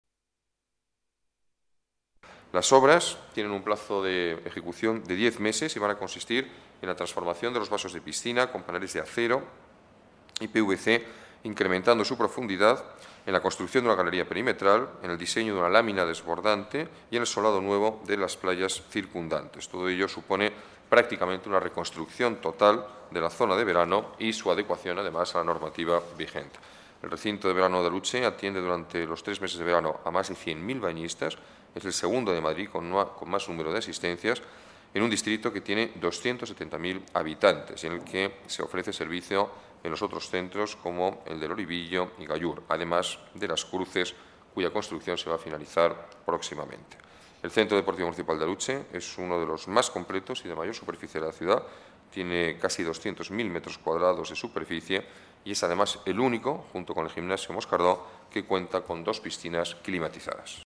Nueva ventana:Declaraciones del acalde sobre mejoras en polideportivo de Aluche